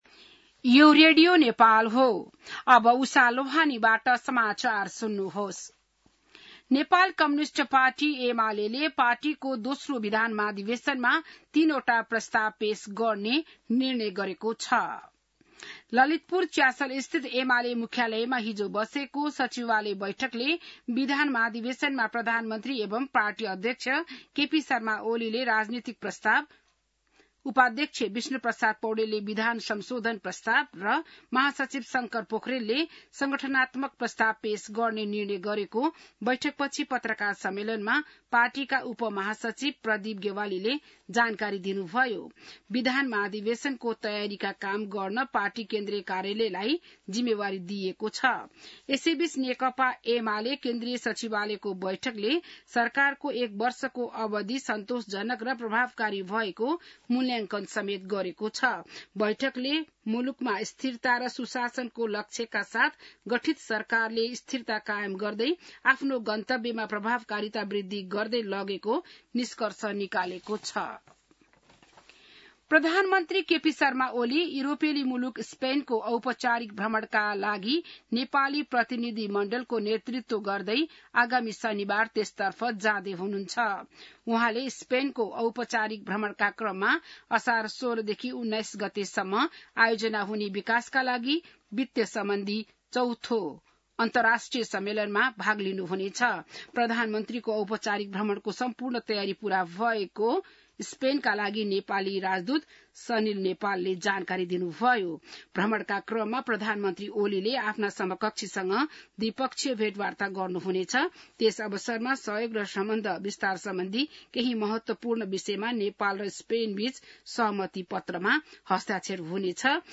बिहान १० बजेको नेपाली समाचार : १२ असार , २०८२